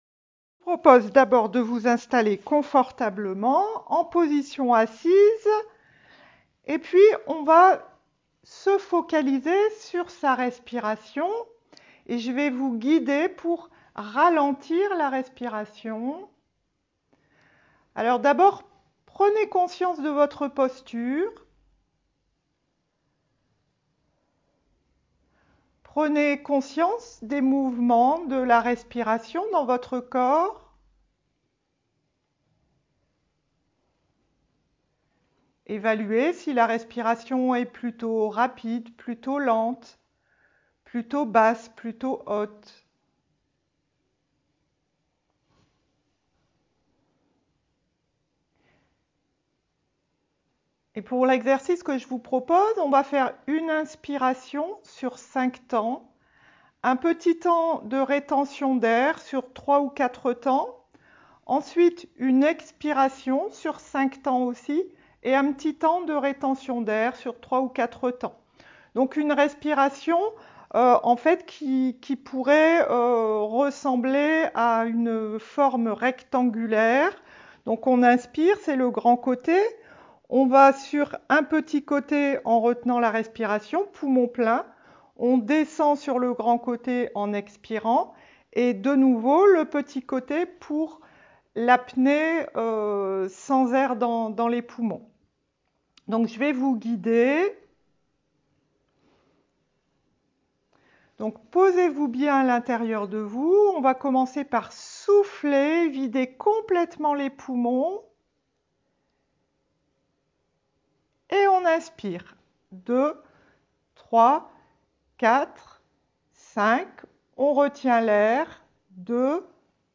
7-Relaxation-dynamique-Se-percevoir-differemment-1.mp3